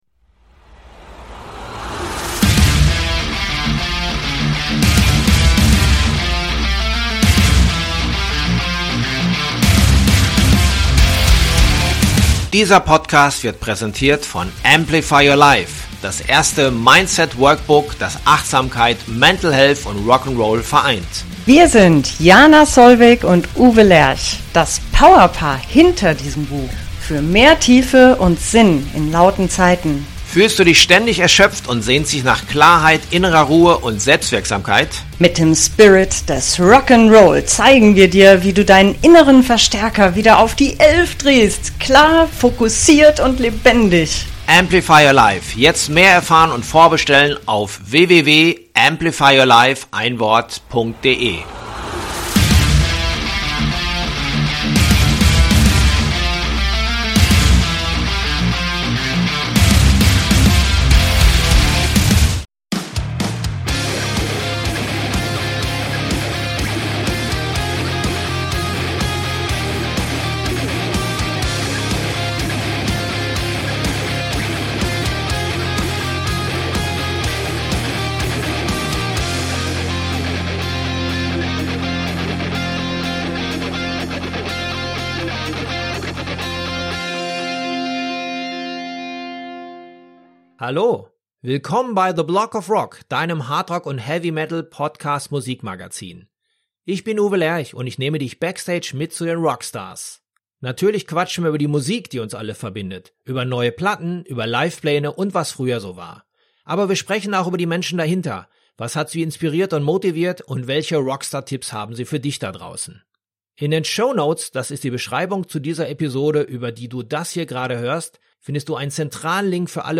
Und wenn ich ihn schon mal an der Strippe habe, was gibt es sonst noch Neues rund um unseren Lieblings-Acker?